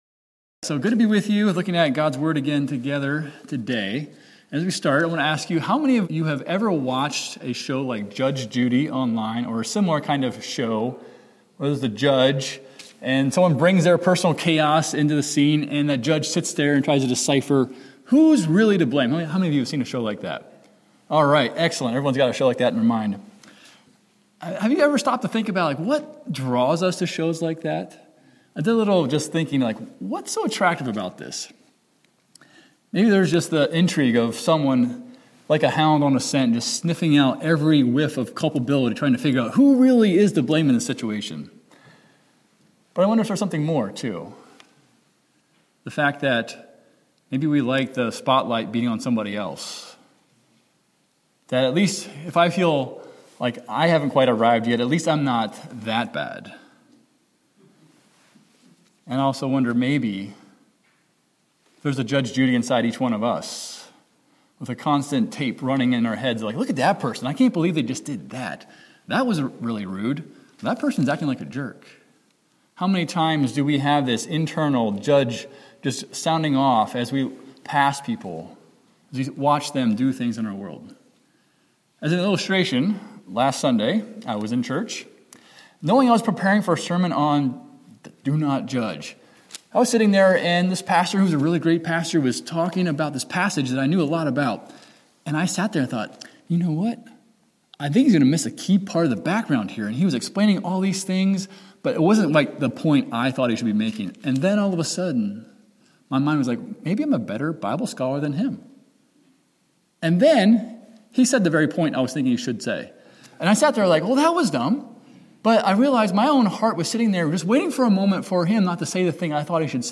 Second Sunday in Lent
Sermon Text: Matthew 7:1–5; 15:1–20